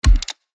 Slot-Spin-Button-1.mp3